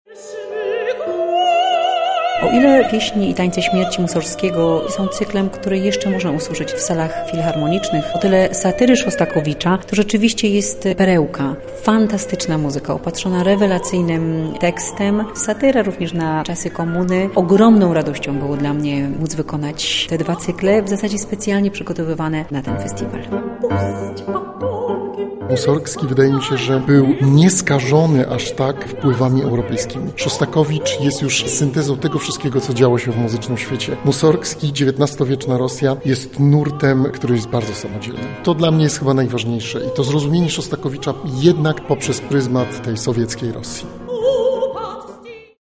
• podsumowują twórcy, którzy wystąpili w ramach „Z kart muzyki rosyjskiej”.